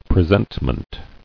[pre·sent·ment]